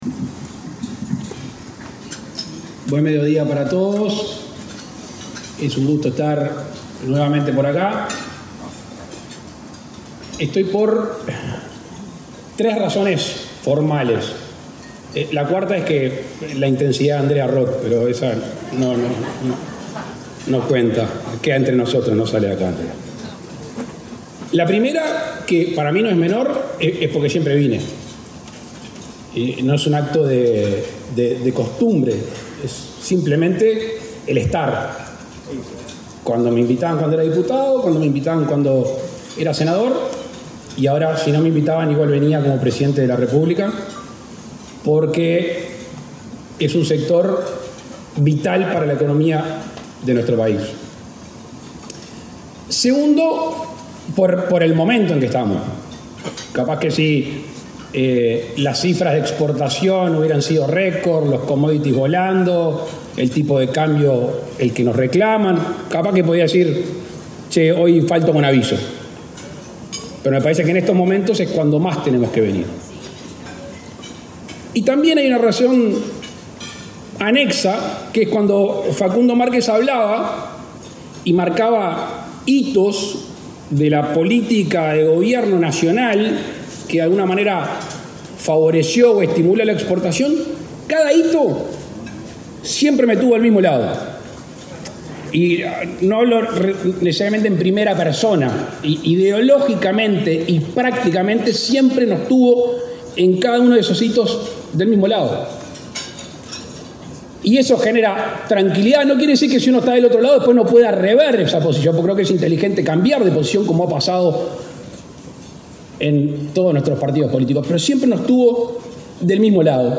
Palabras del presidente Luis Lacalle Pou
El presidente Luis Lacalle Pou participó, este jueves 28 en Montevideo, de la celebración por el Día del Exportador.